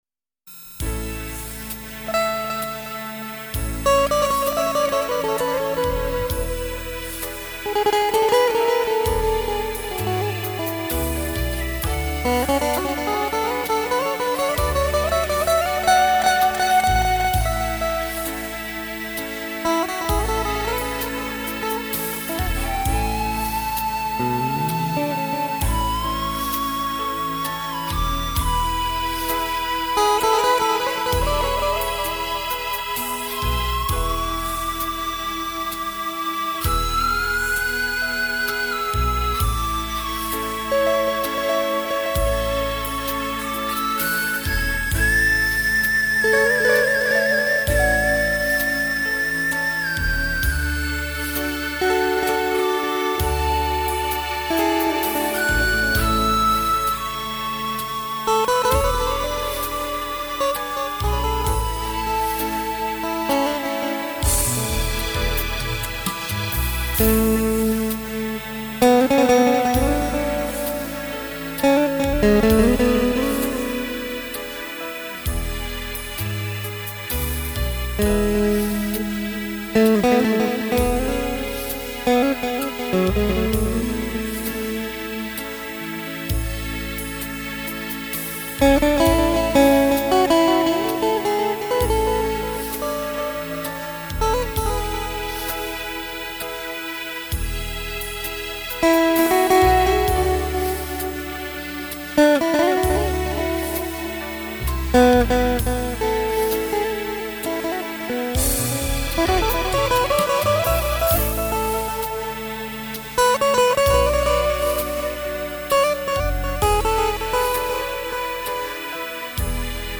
* Ca sĩ: Không lời